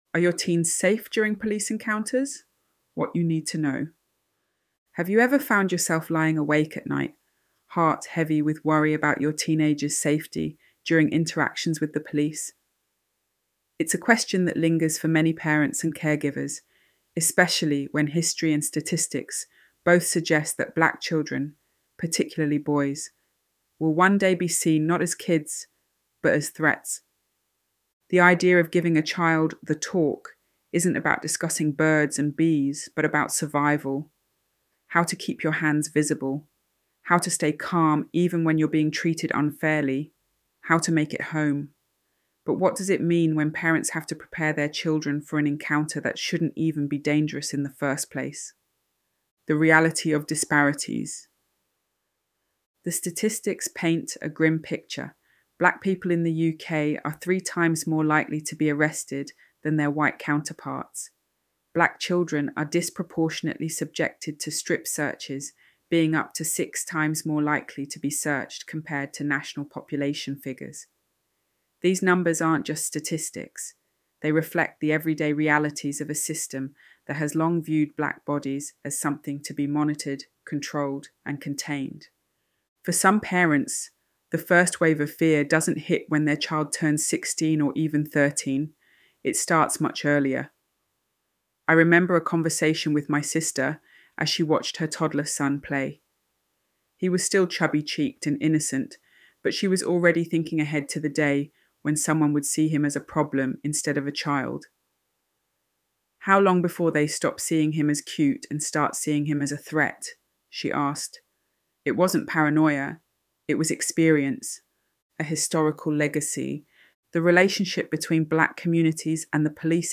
ElevenLabs_Are-Your-Teens-Safe-During-Police-Encounters-What-You-Need-to-Know.mp3